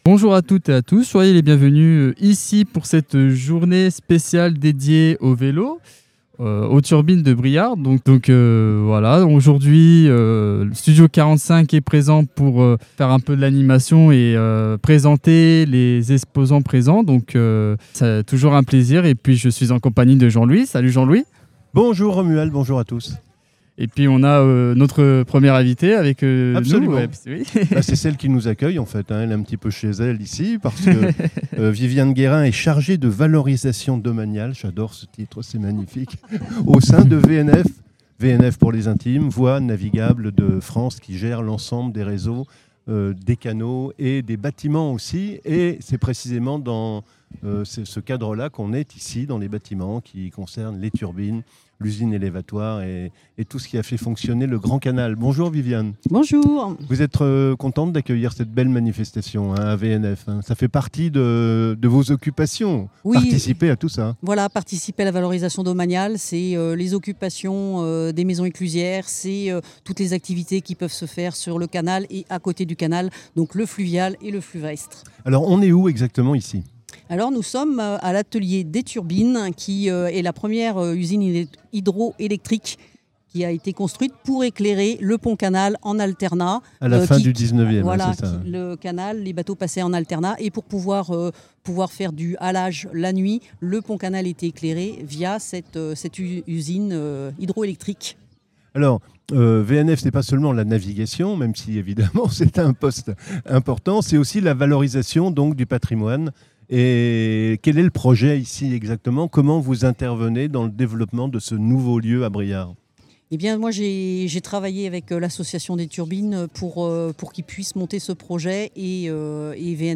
Interview
À l’occasion du Grand Répar Vélo des Turbines, Studio 45 vous propose une série d’interviews réalisées en direct du Pont Canal de Briare.
Entre rencontres humaines, initiatives locales et ambiance conviviale, plongez au cœur d’un événement engagé autour du vélo, du recyclage et du vivre-ensemble.